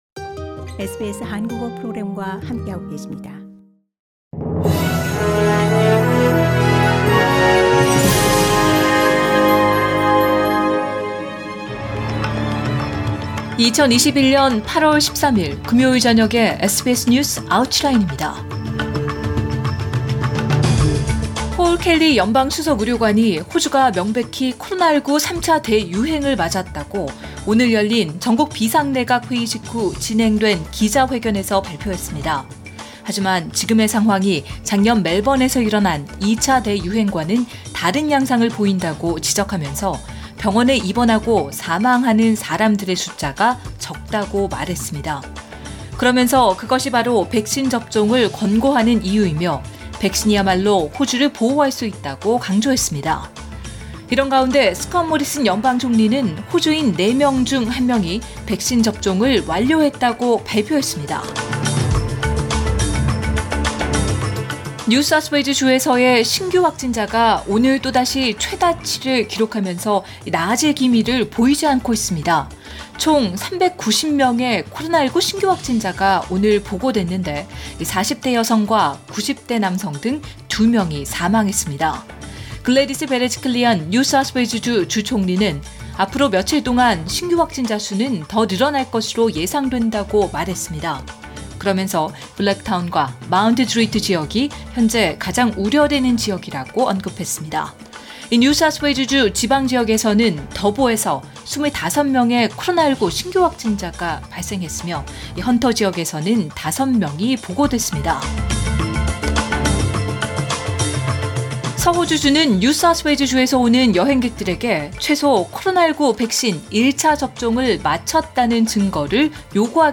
2021년 8월 13일 금요일 저녁의 SBS 뉴스 아우트라인입니다.